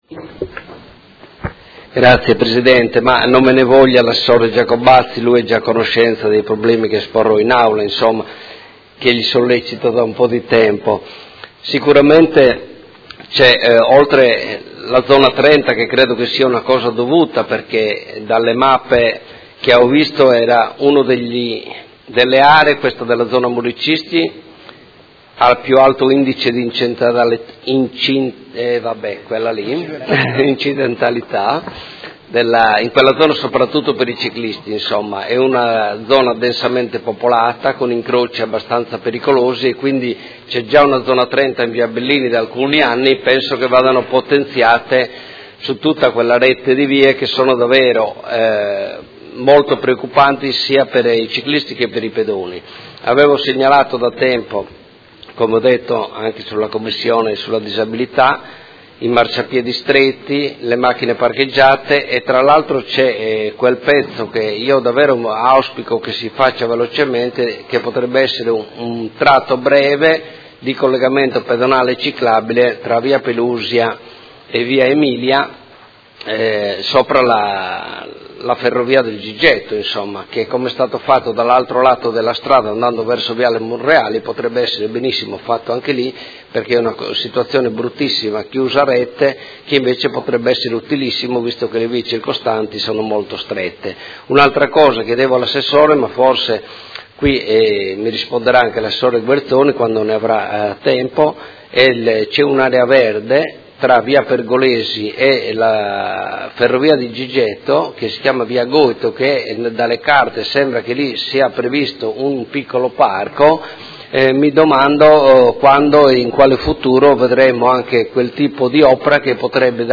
Seduta del 26/10/2017. Dibattito su interrogazione del Gruppo Consiliare Per Me Modena avente per oggetto: Viabilità zona San Pio X